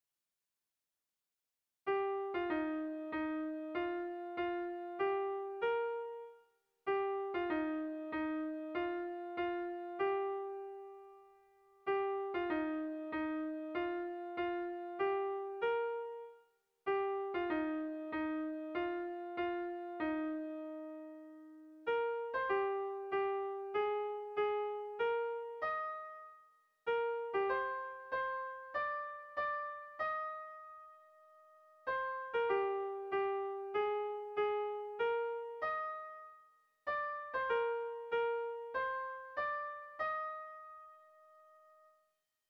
Bertso melodies - View details   To know more about this section
Sentimenduzkoa
A1A2B1B2